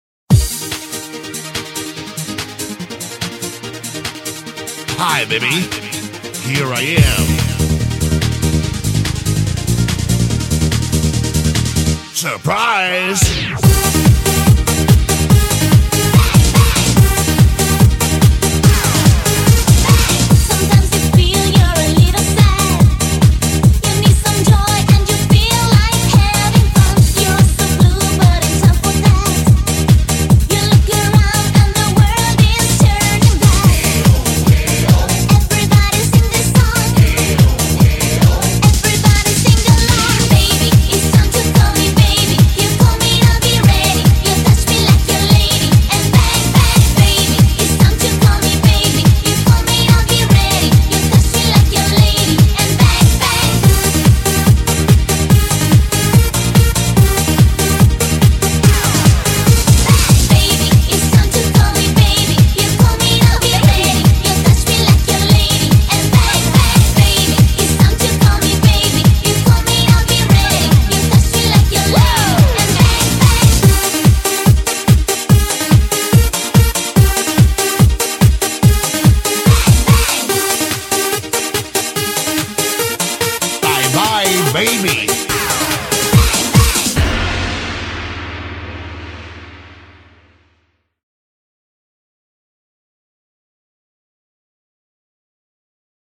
BPM143--1
Audio QualityPerfect (High Quality)